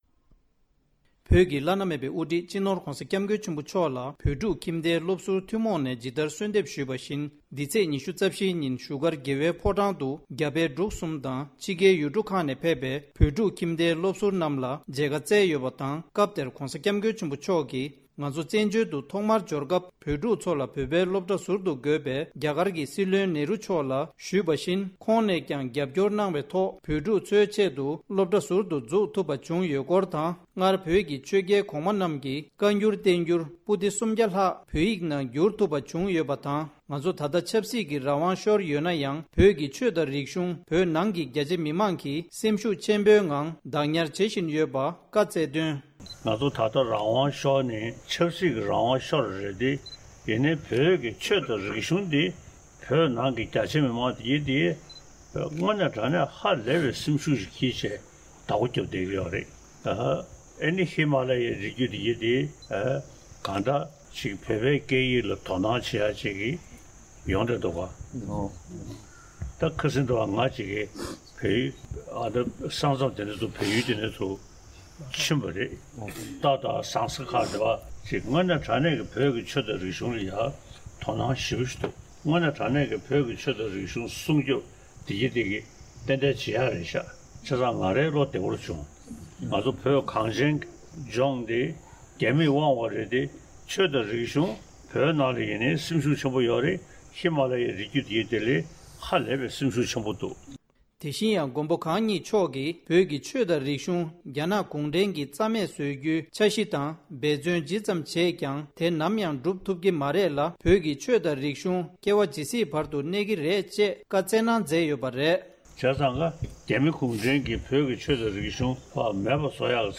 བོད་ཀྱི་ཆོས་དང་རིག་གཞུང་རྒྱ་ནག་གུང་ཁྲན་གྱིས་རྩ་མེད་བཟོ་རྒྱུའི་འབད་བརྩོན་བྱས་ཡོད་ཀྱང་འགྲུབ་མེད་ལ་ནམ་ཡང་འགྲུབ་ཐུབ་ཀྱི་མ་རེད། ༧གོང་ས་མཆོག་གིས། ༧གོང་ས་མཆོག་གིས་བོད་ཕྲུག་ཁྱིམ་སྡེའི་སློབ་ཟུར་རྣམས་ལ་མཇལ་ཁའི་སྐབས་བཀའ་བསྩལ་སྐབས། ༡༠།༢༤།༢༠༢༢
སྒྲ་ལྡན་གསར་འགྱུར།